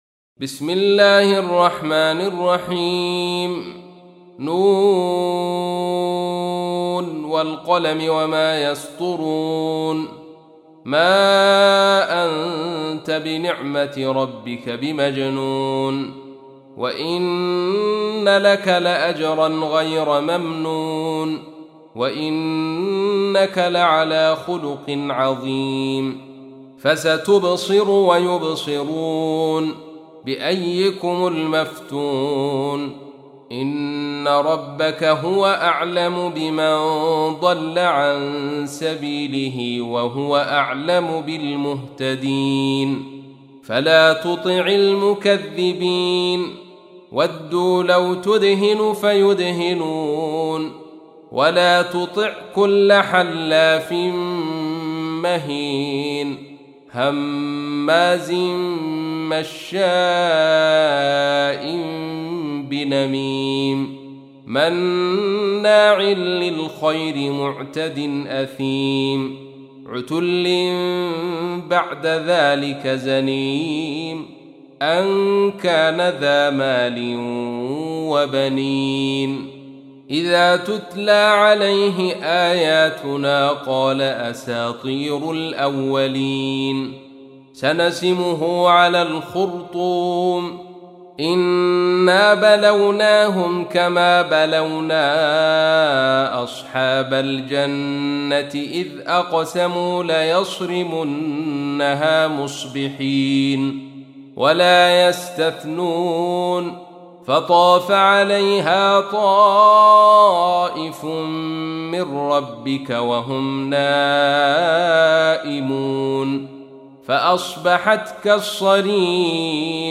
تحميل : 68. سورة القلم / القارئ عبد الرشيد صوفي / القرآن الكريم / موقع يا حسين